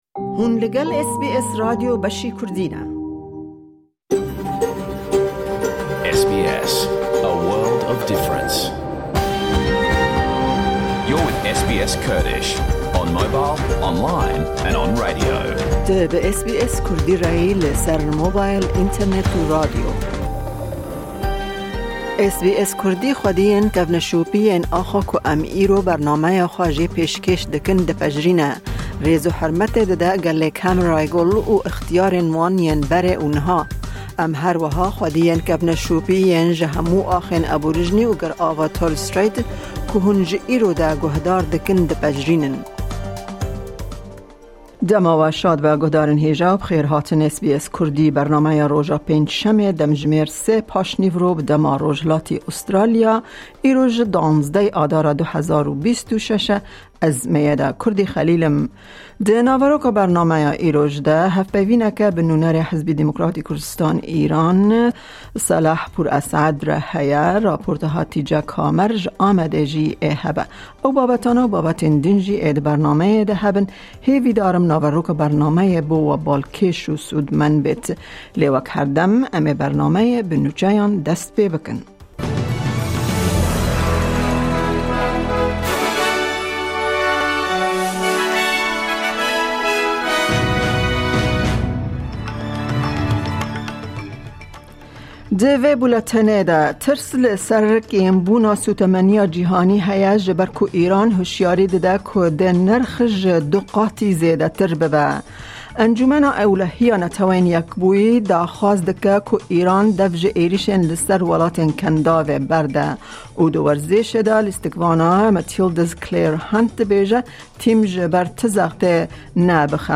Nûçe, raportên ji Hewlêr û Amedê, hevpeyvîn û gelek babetên di yên cur bi cur di naveroka bernameyê de tên dîtin.